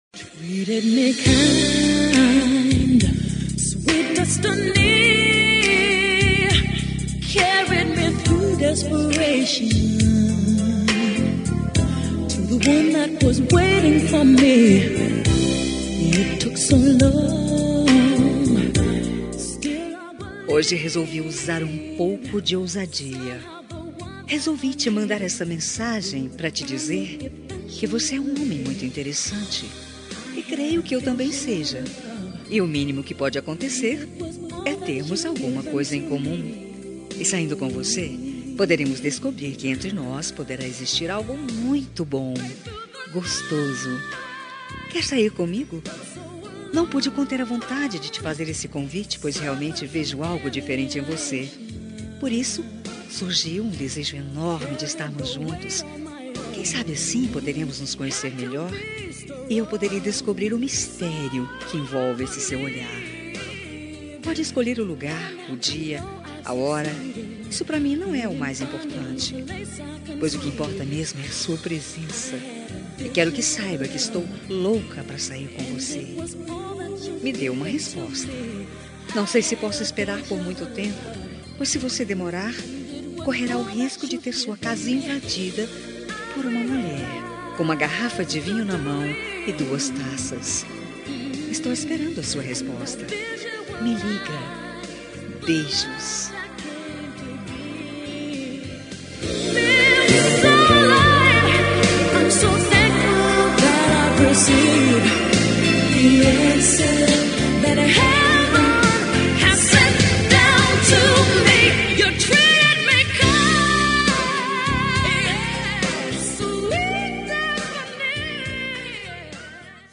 Telemensagem de Pedido – Voz Masculina – Cód: 4166 -Quer sair comigo